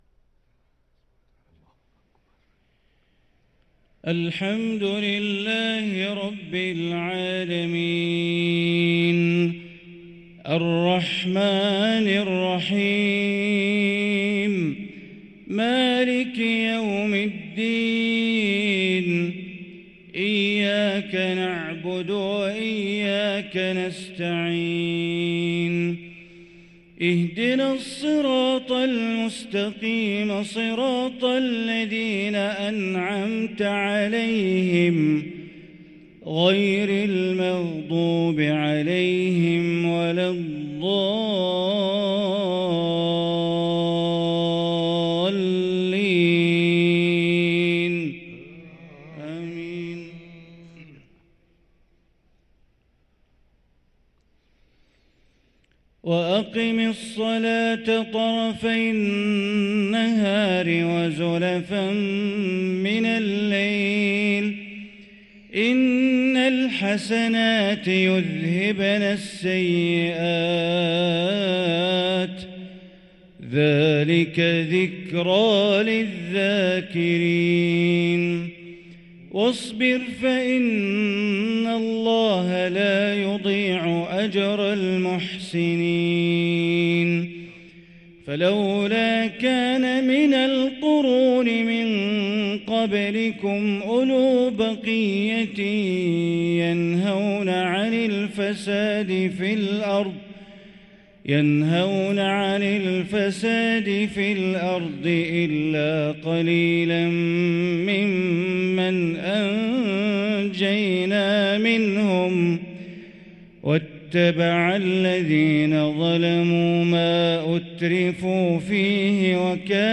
صلاة العشاء للقارئ بندر بليلة 3 شوال 1444 هـ